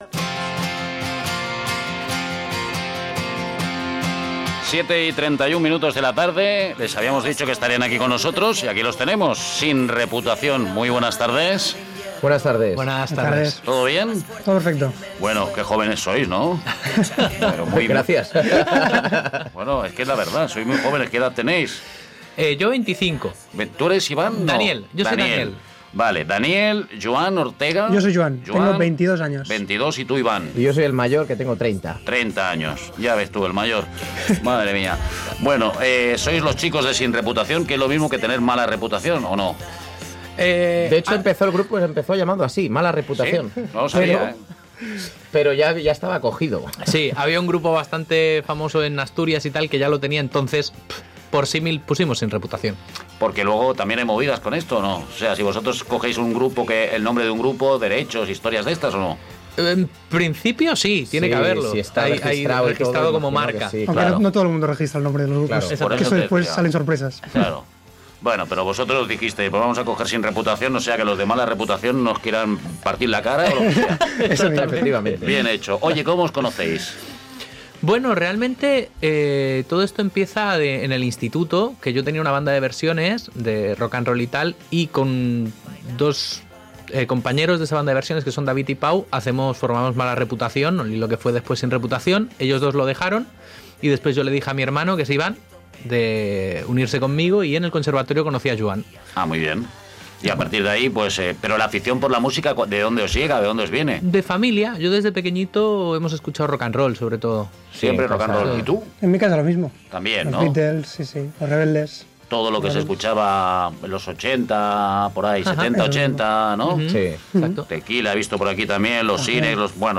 entrev-sin-reputacion.mp3